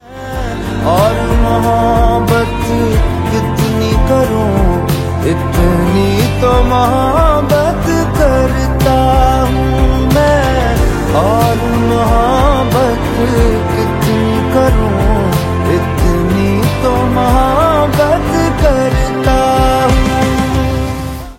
deeply emotional romantic song